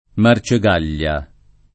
Marcegaglia [ mar © e g# l’l’a ] cogn.